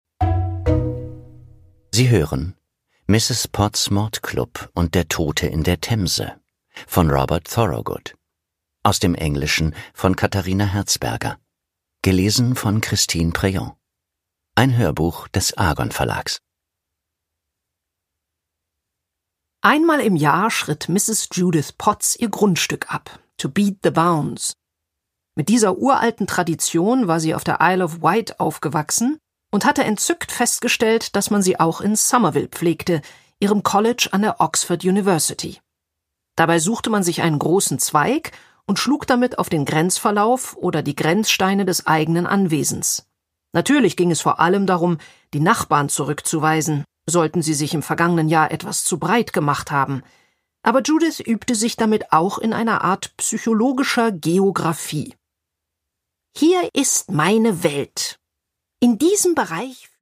Produkttyp: Hörbuch-Download
Gelesen von: Christine Prayon